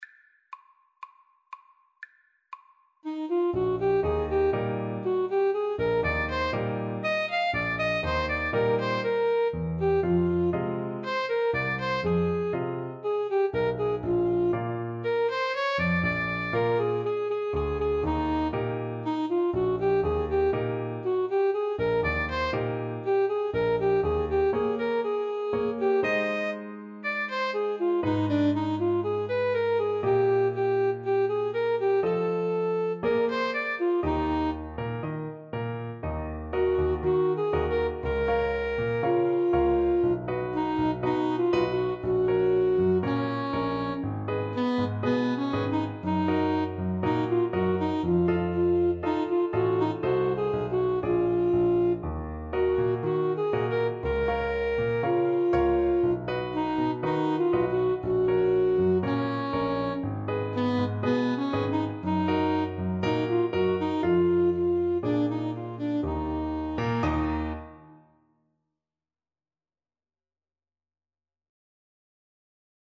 ClarinetAlto Saxophone
2/4 (View more 2/4 Music)
World (View more World Clarinet-Saxophone Duet Music)